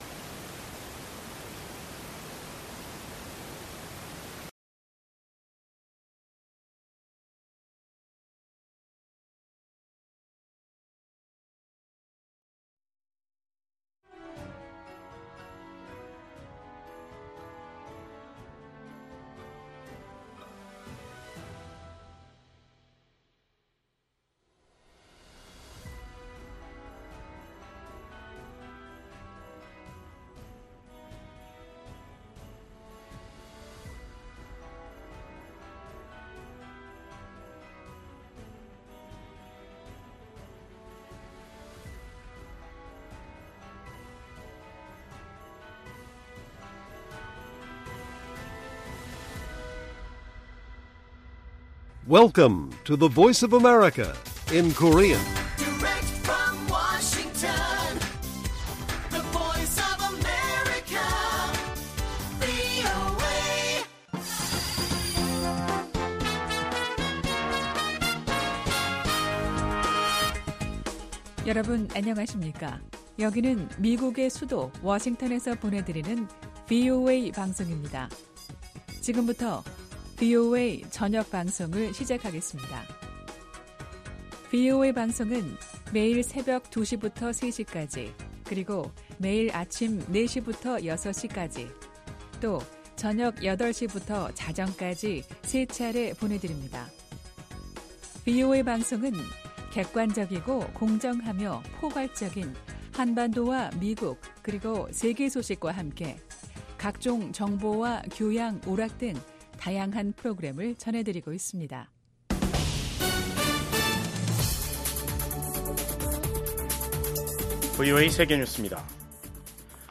VOA 한국어 간판 뉴스 프로그램 '뉴스 투데이', 2024년 12월 18일 1부 방송입니다. 러시아에 파병된 북한군에서 수백 명의 사상자가 발생했다고 미군 고위 당국자가 밝혔습니다. 미국 국무부는 한국 대통령 탄핵소추안 통과와 관련해 한국 헌법 절차가 취지대로 작동하고 있다고 평가했습니다.